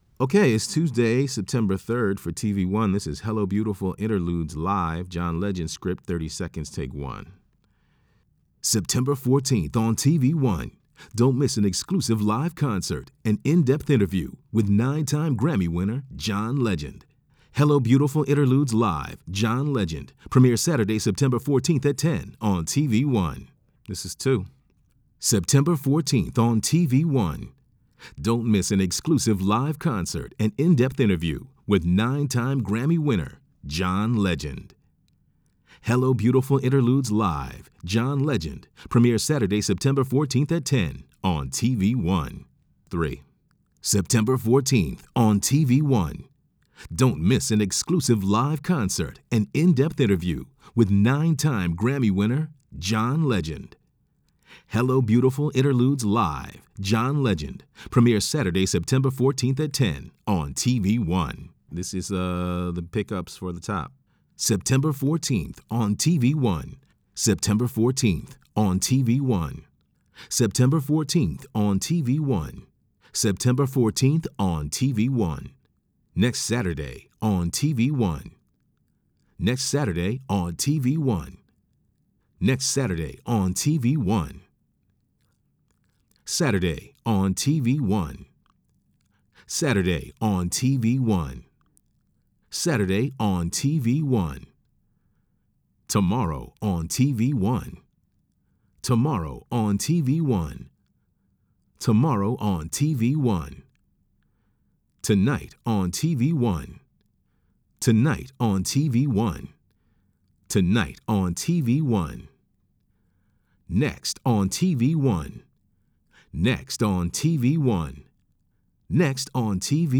HELLO BEAUTIFUL INTERLUDES LIVE.aif